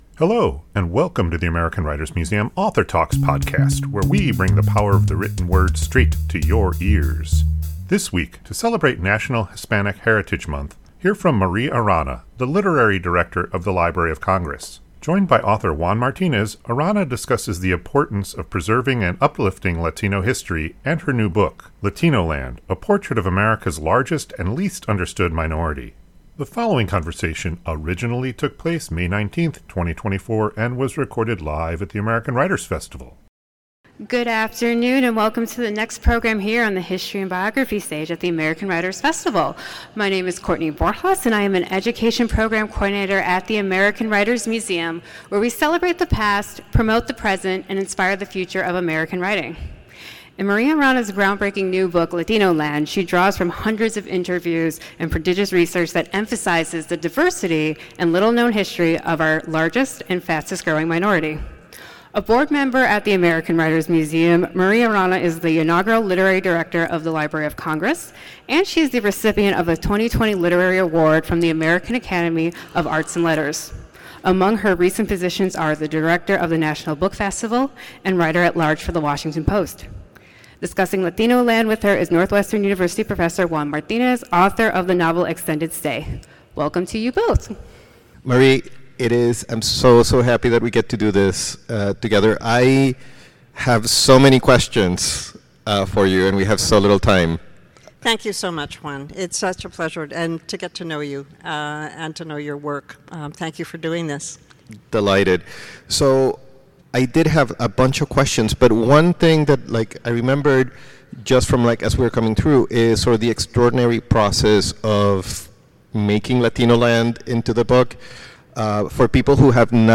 This conversation originally took place May [...]